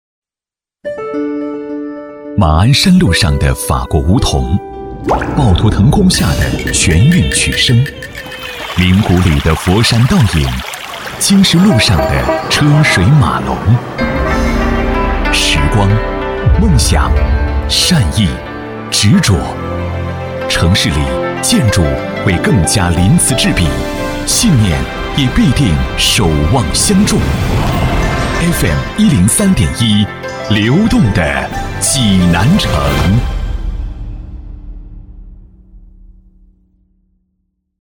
专题片/宣传片配音-纵声配音网
男24 - 流动的济南城市 自然叙述
男24 【电台宣传】流动的济南城市.mp3